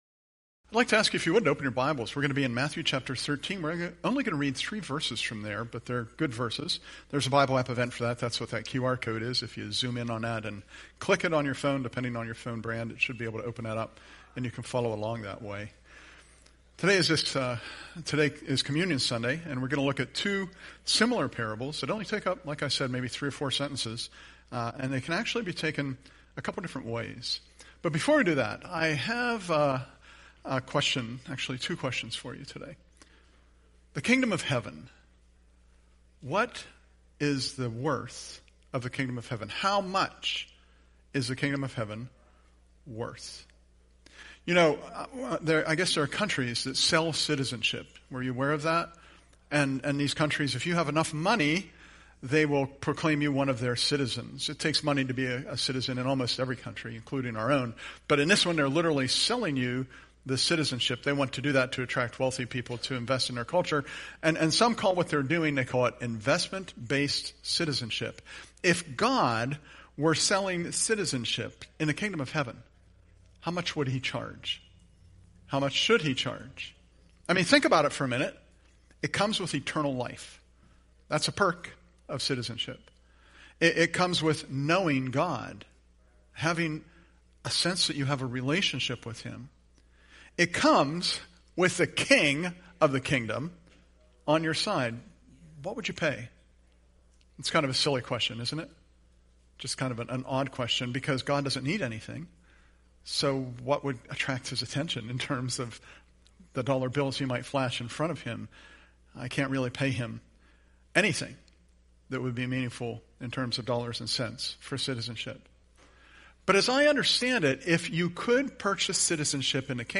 Presented at Curwensville Alliance on 8/3/25